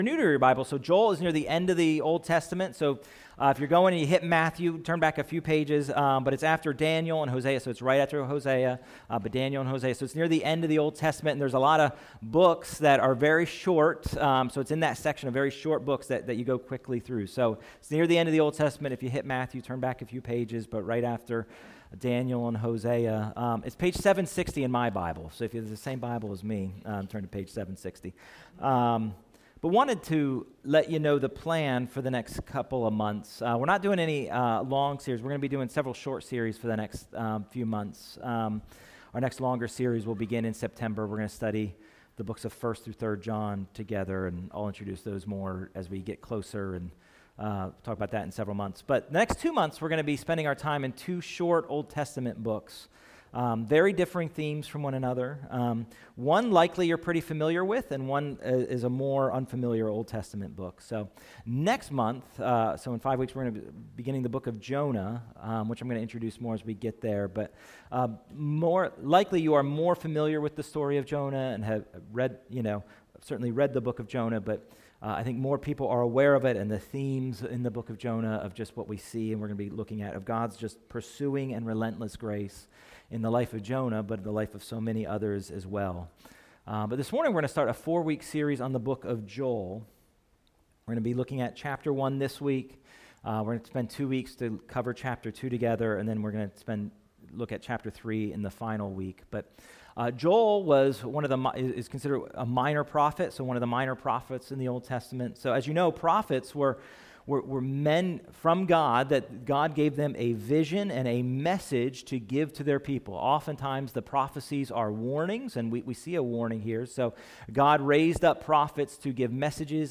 » Sermons